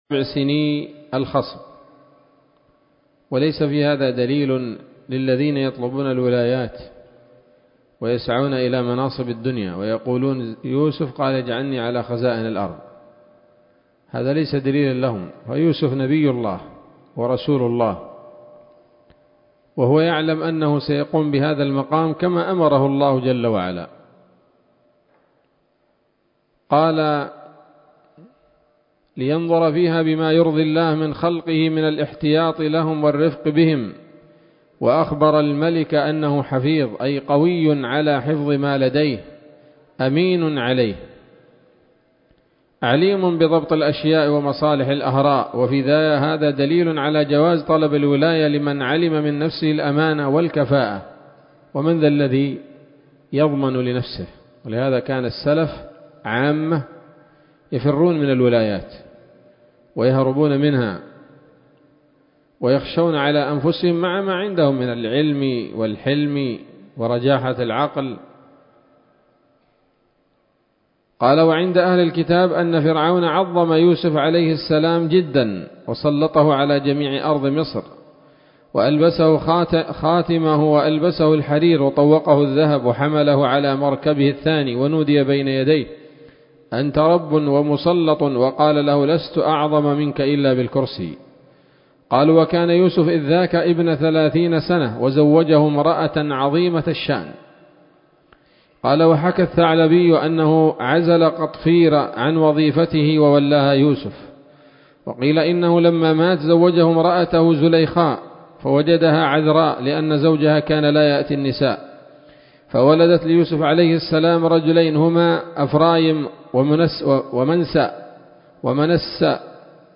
الدرس السبعون من قصص الأنبياء لابن كثير رحمه الله تعالى